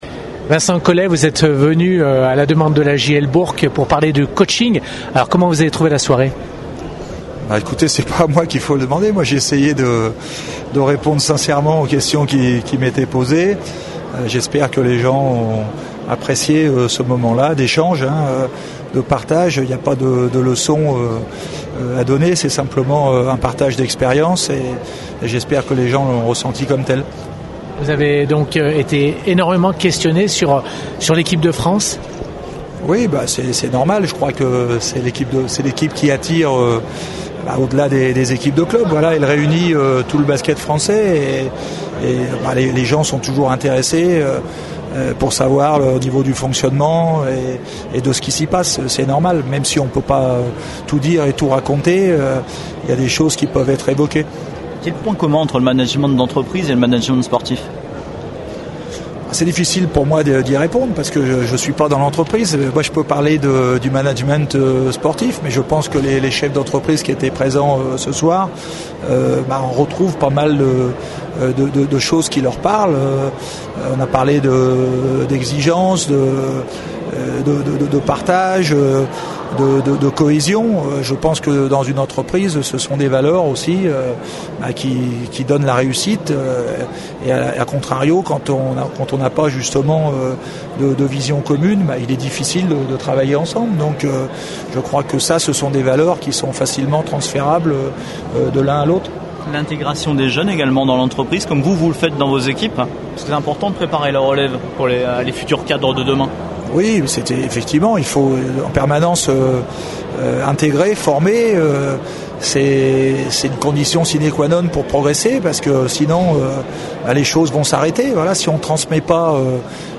au micro de Radio Scoop